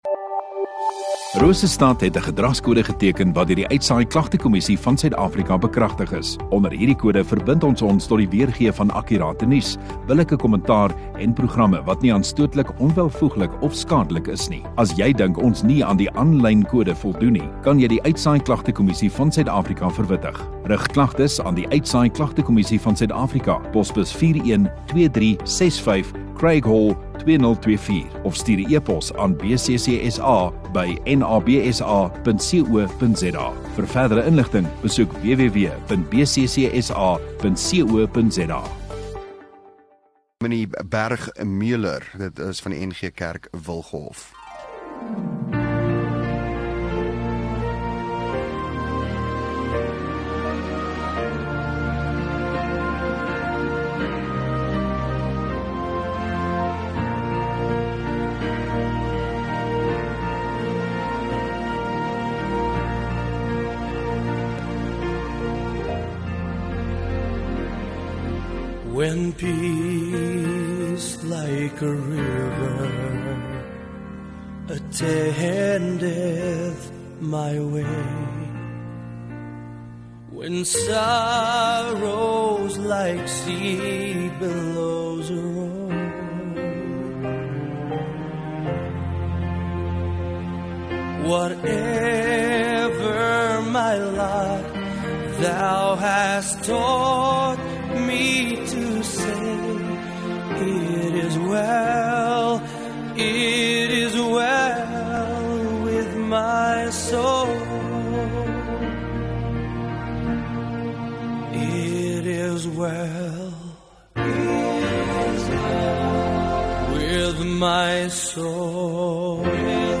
15 Jul Dinsdag Oggenddiens